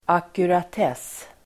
Ladda ner uttalet
Uttal: [akurat'es:]
ackuratess.mp3